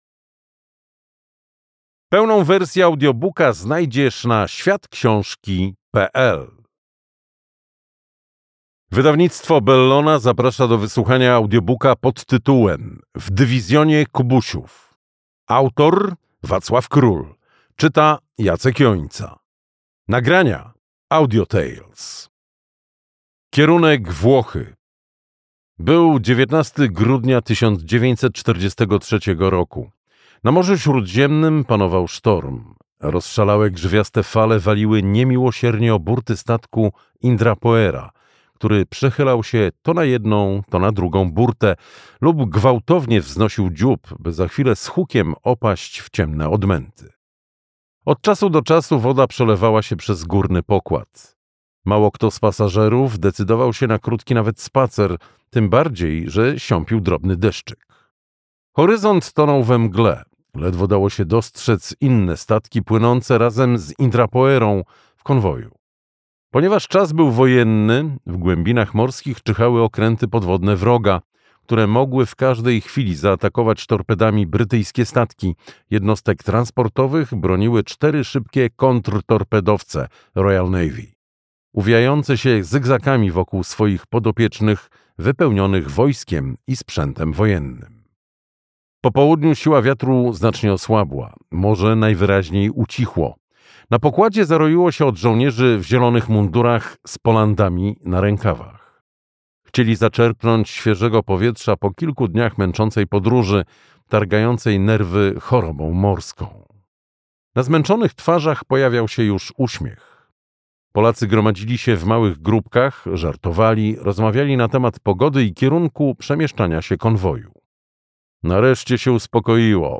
W dywizjonie Kubusiów - Wacław Król - audiobook